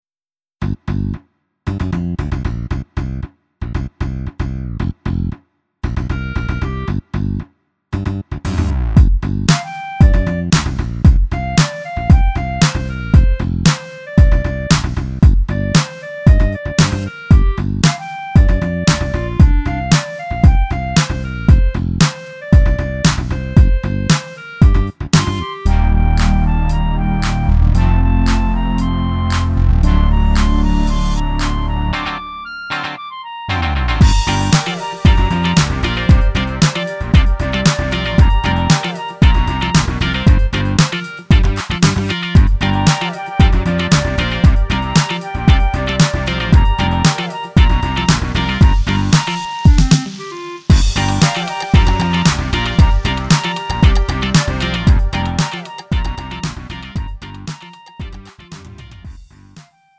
음정 원키 2:29
장르 가요 구분 Lite MR
Lite MR은 저렴한 가격에 간단한 연습이나 취미용으로 활용할 수 있는 가벼운 반주입니다.